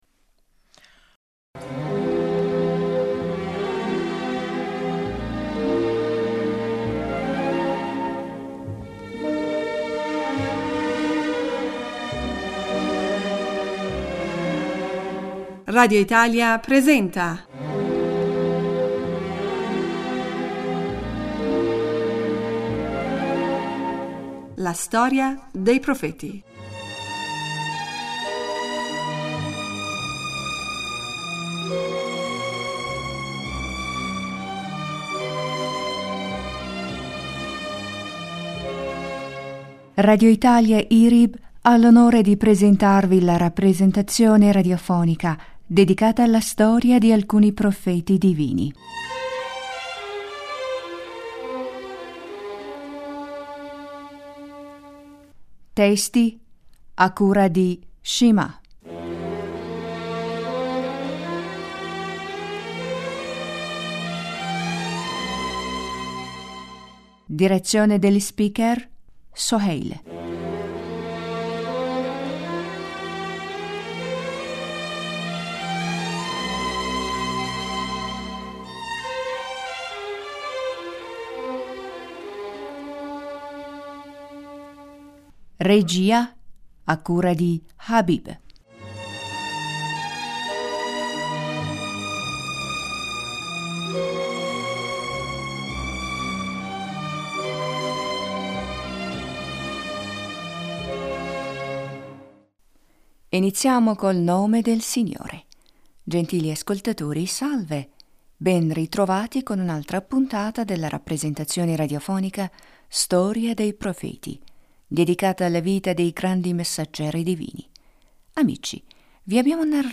Gentili ascoltatori salve, benritrovati con un’altra puntata della rappresentazione radiofonica “Storia dei Pr...